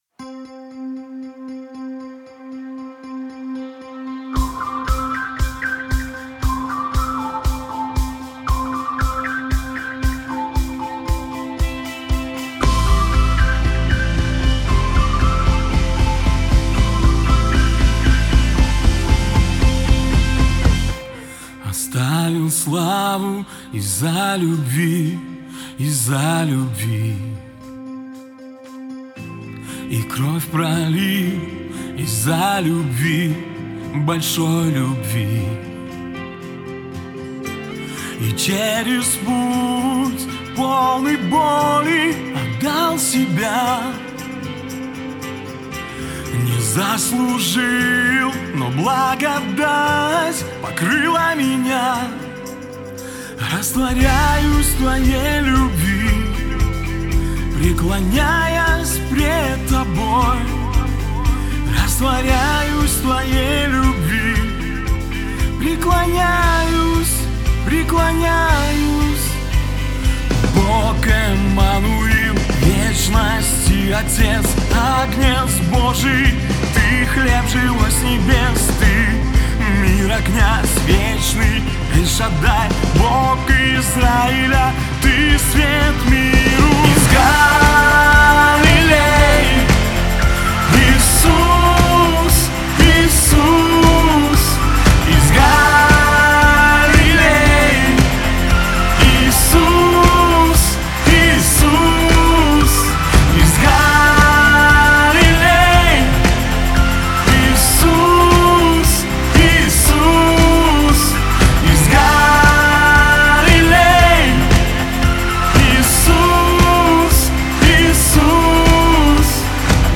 2977 просмотров 1519 прослушиваний 222 скачивания BPM: 116